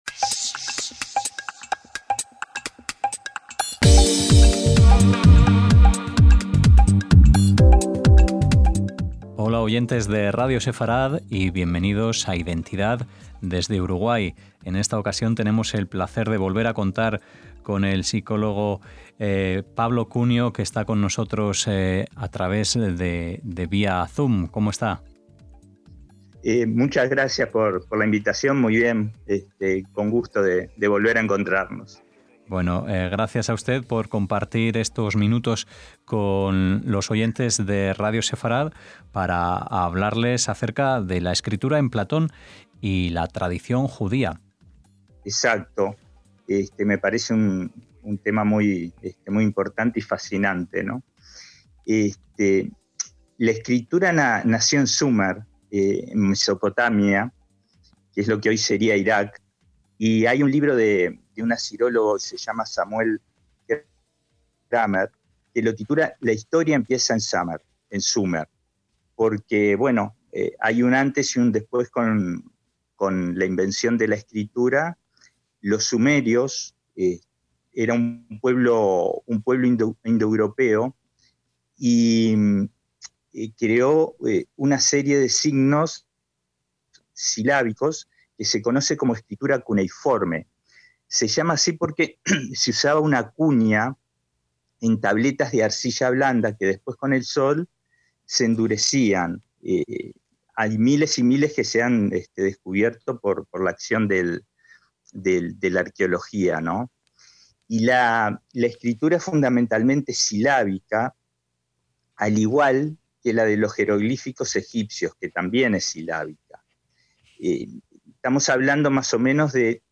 En esta ocasión nos acompaña el psicólogo